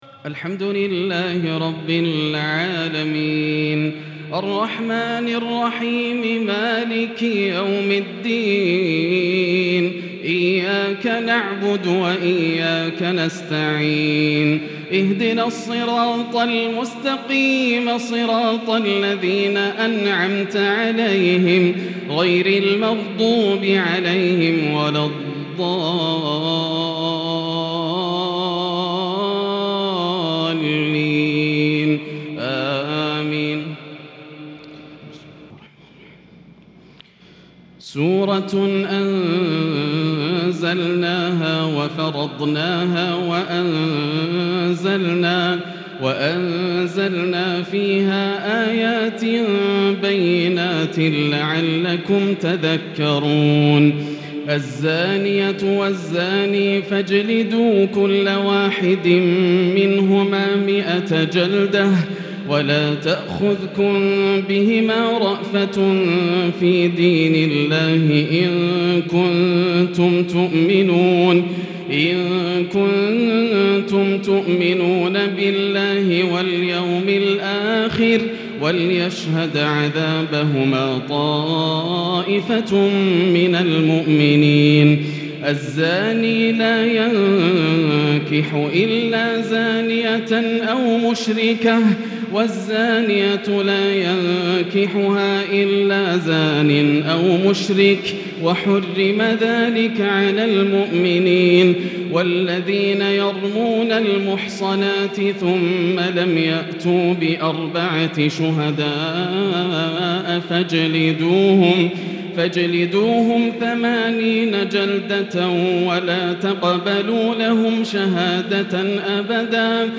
صلاة التهجّد | ليلة 22 رمضان 1442| سورة النور كاملة | Tahajjud prayer | The night of Ramadan 22 1442 | Surah Nour > تراويح الحرم المكي عام 1442 🕋 > التراويح - تلاوات الحرمين